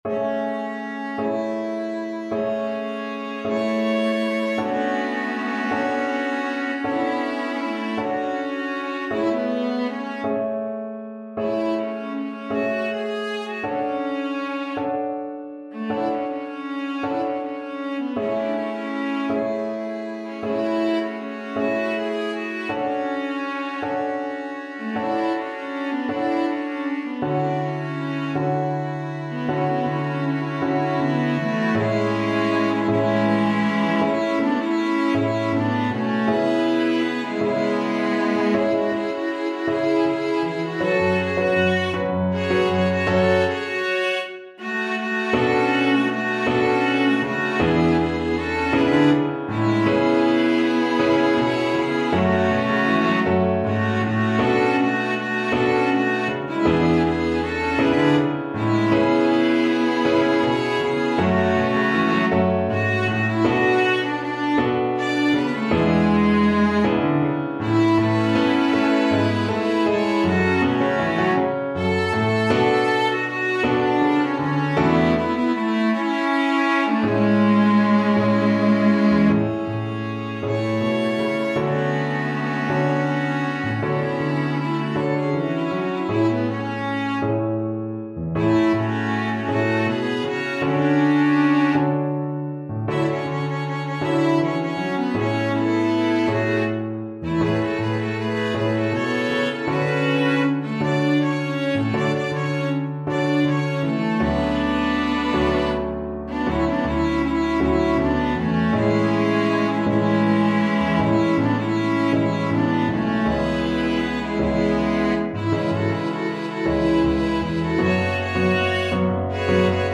SSAA + piano/band